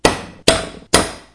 Stonecutter SFX
Short stonecutter sound.
stonecutter_0.ogg